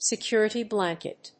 アクセントsecúrity blànket